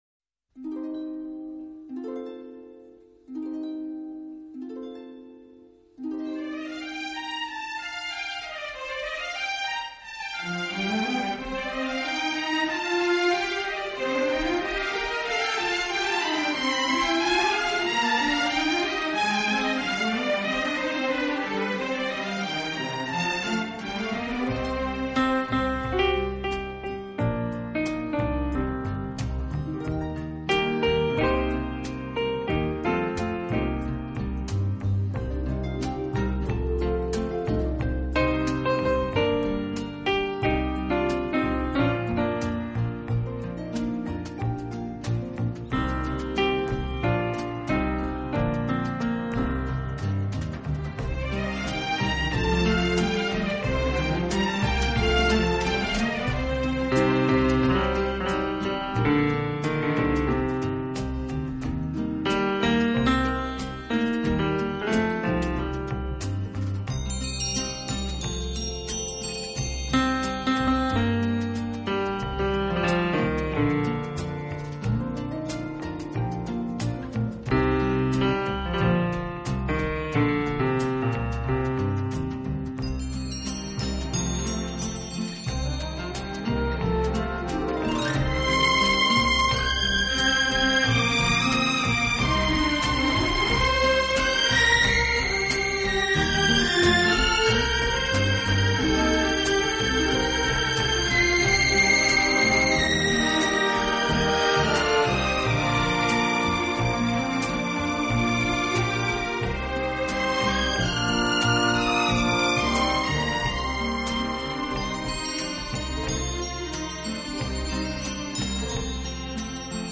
除此之外，他在选择乐队伴奏时也非常考究，常使用弦乐来
勾勒出华丽而精致的背景，虽然间或也会采用打击乐。